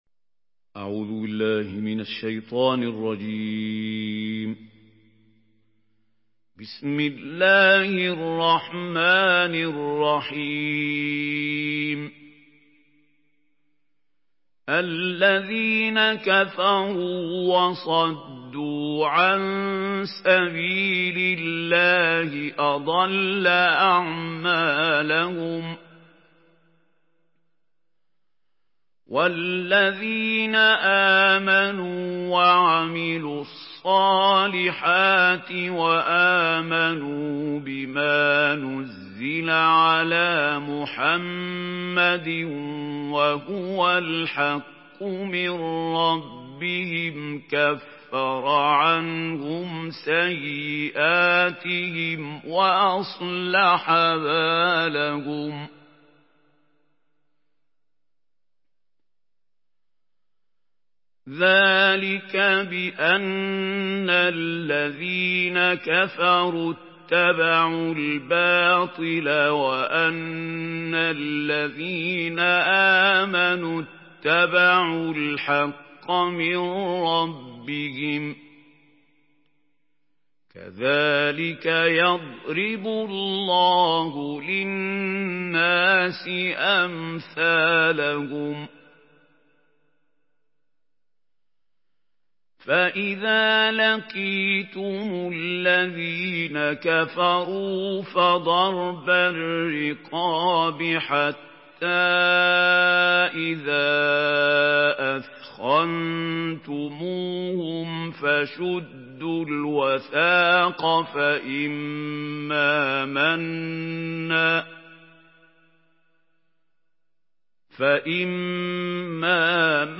Surah মুহাম্মাদ MP3 in the Voice of Mahmoud Khalil Al-Hussary in Hafs Narration
Surah মুহাম্মাদ MP3 by Mahmoud Khalil Al-Hussary in Hafs An Asim narration.
Murattal Hafs An Asim